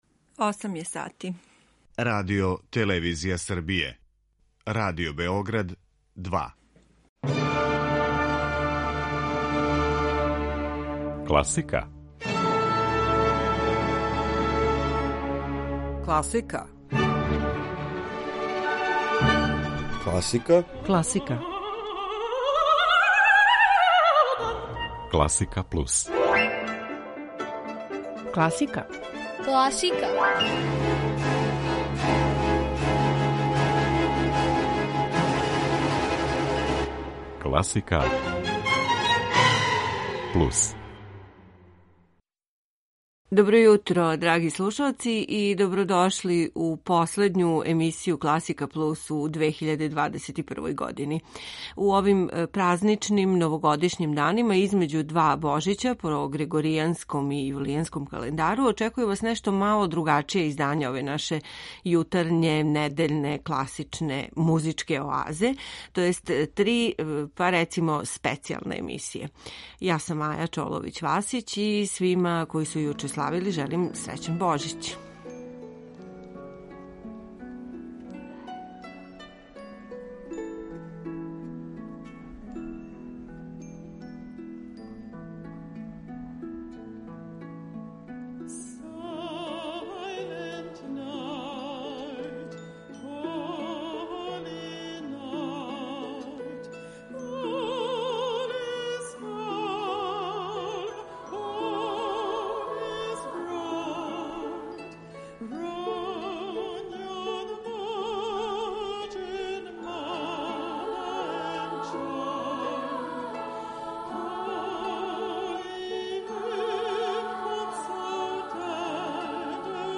Емисија класичне музике